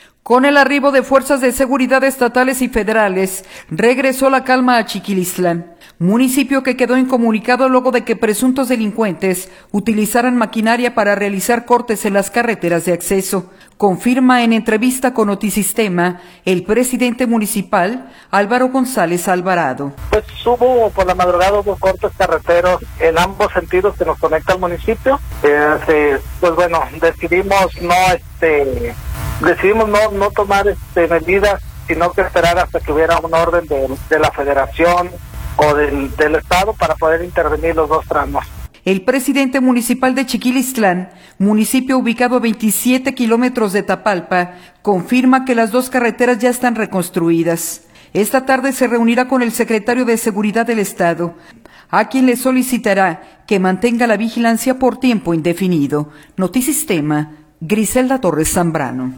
Con el arribo de fuerzas de seguridad estatales y federales regresó la calma a Chiquilistlán, municipio que quedó incomunicado luego de que presuntos delincuentes utilizaran maquinaria para realizar cortes en las carreteras de acceso, confirma en entrevista con Notisistema el presidente municipal, […]